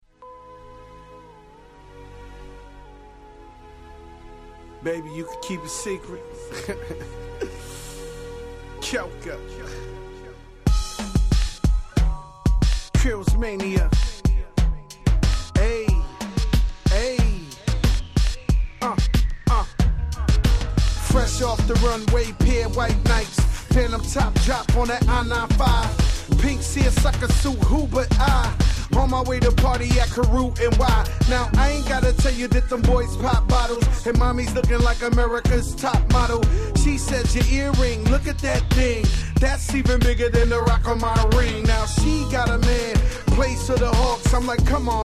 07' Big Hit Hip Hop !!